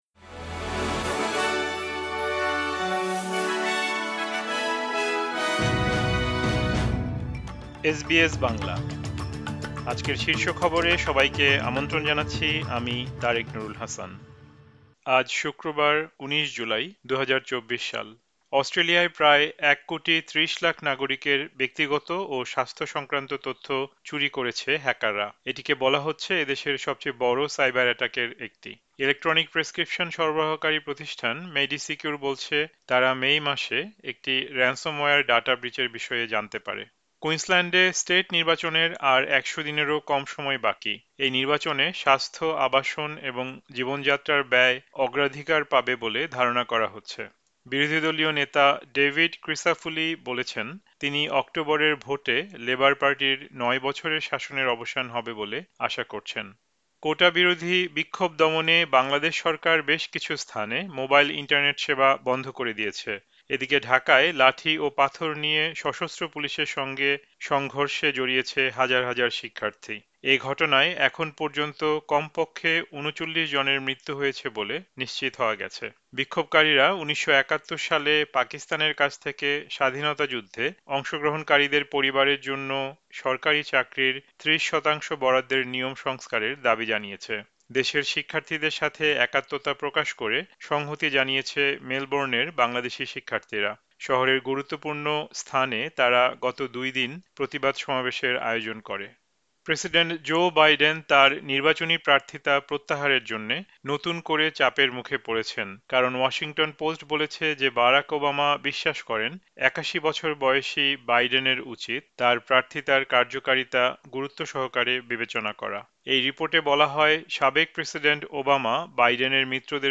এসবিএস বাংলা শীর্ষ খবর: ১৯ জুলাই, ২০২৪